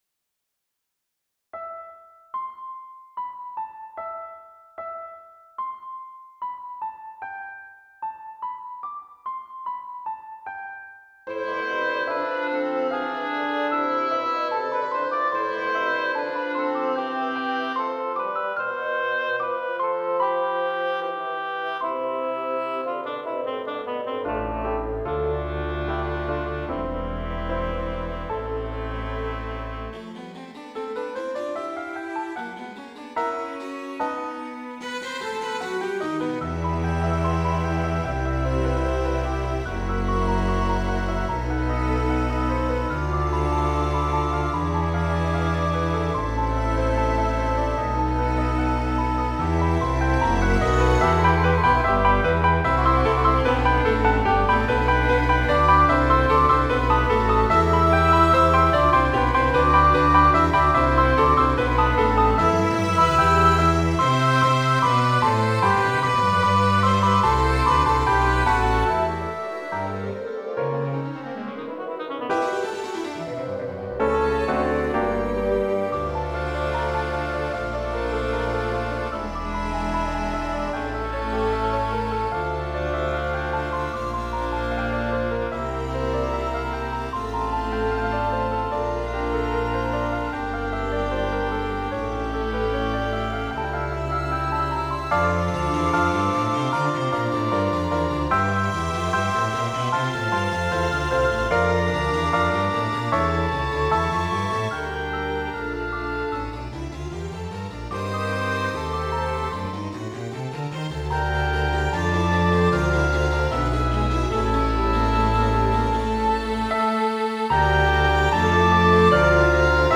(a-moll)